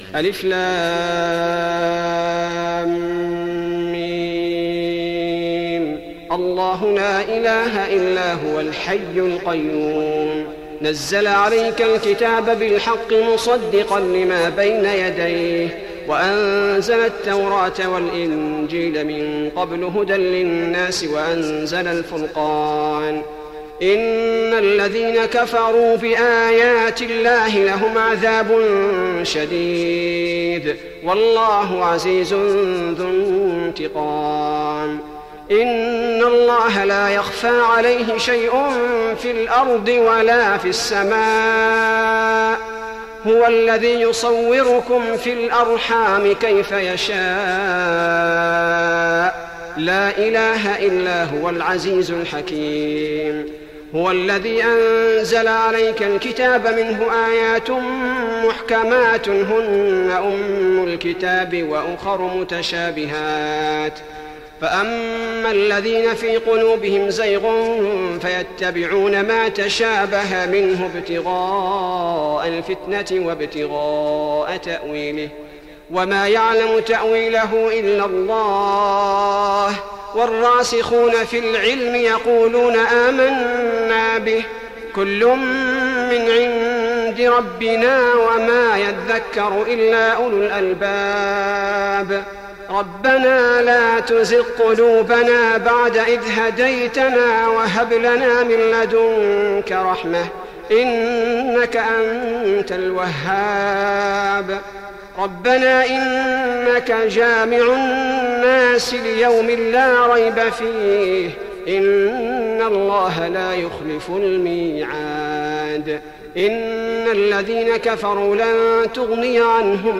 تهجد رمضان 1415هـ من سورة آل عمران (1-27) Tahajjud Ramadan 1415H from Surah Aal-i-Imraan > تراويح الحرم النبوي عام 1415 🕌 > التراويح - تلاوات الحرمين